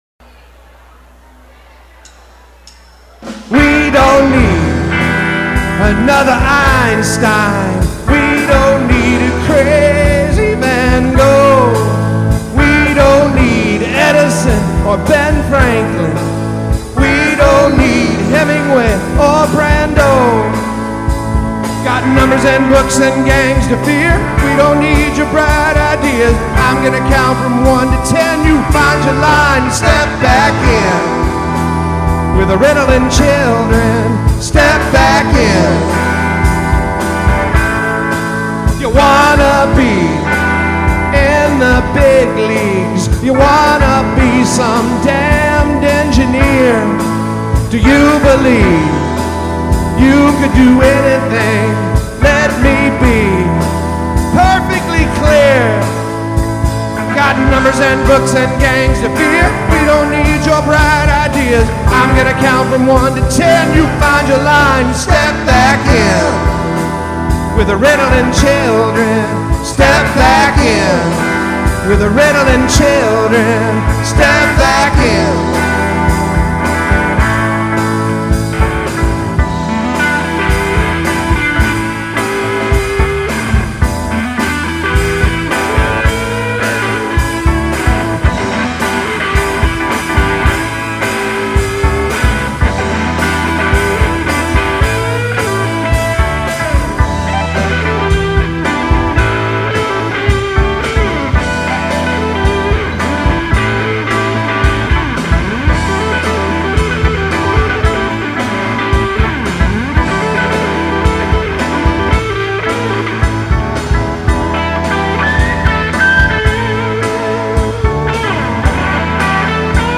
recorded live May 13, 2006
River Raisin Center for the Arts, Monroe, MI
vocals, guitar
bass, vocals
piano, organ, keyboards
drums